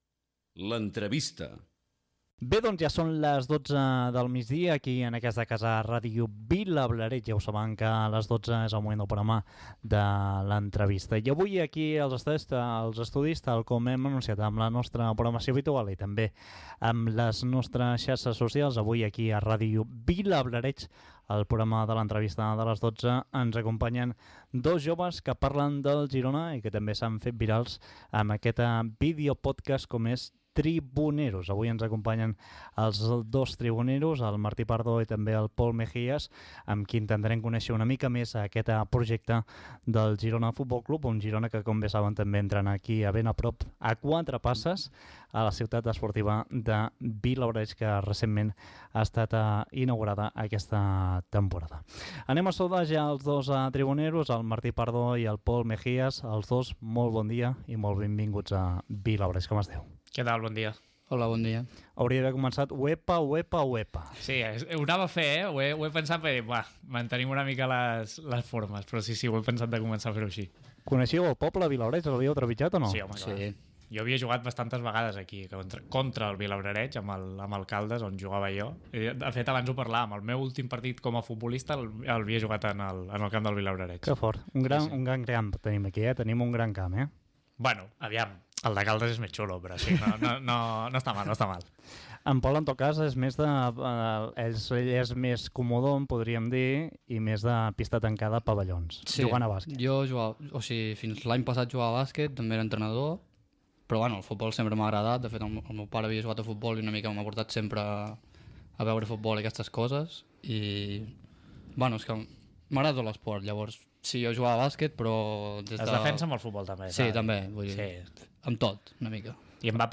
Identificació de l'emissora i del programa, entrevista
Gènere radiofònic Entreteniment